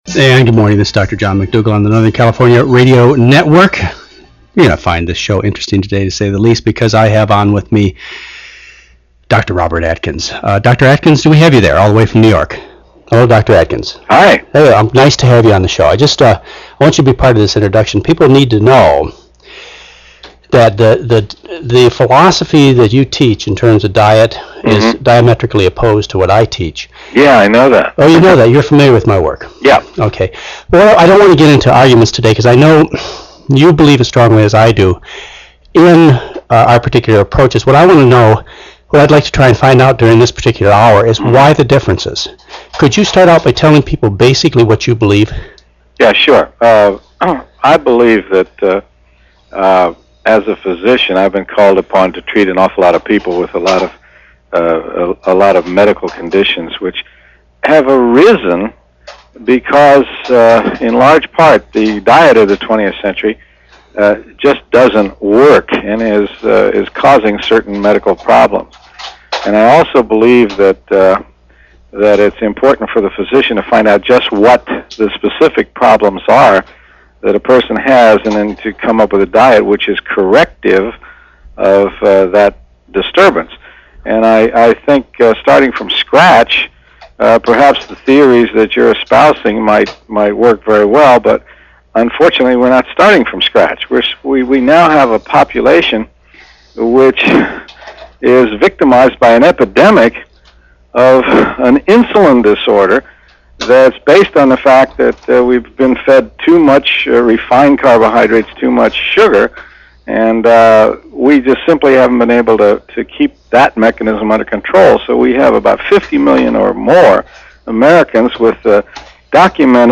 Robert Atkins, MD (taped interview)
Note: This taped interview from “Your Good Health,” hosted by Dr. McDougall has been edited.